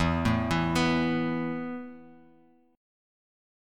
Esus4 Chord